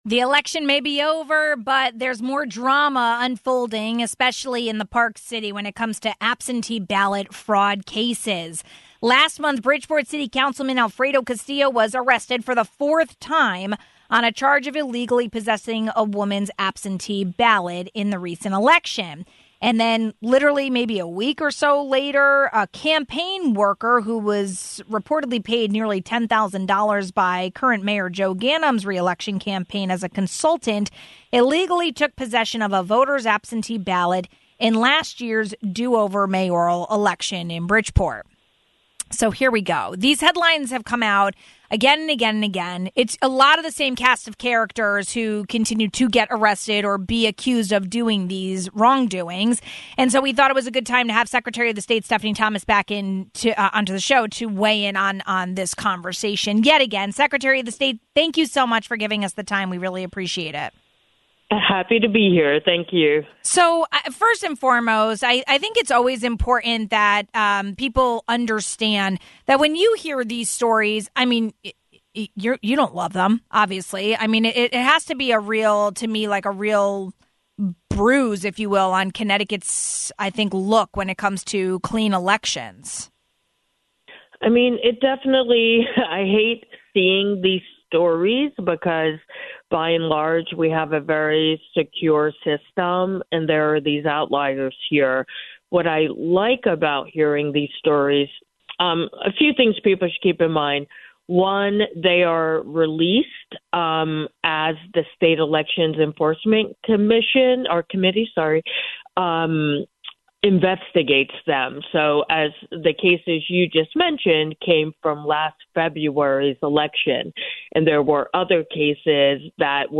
What can be done to stop bad actors from allegedly committing the same crimes? We spoke with Secretary of the State Stephanie Thomas.